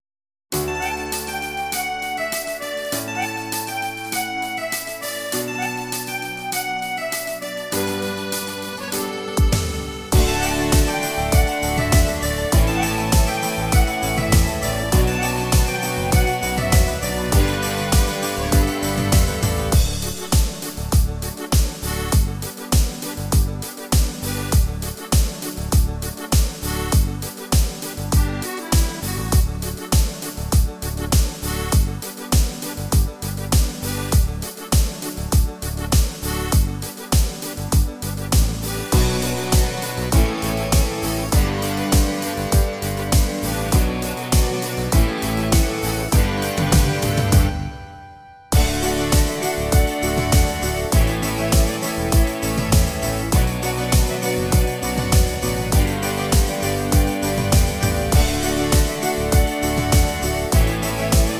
Karnevals Hit us Kölle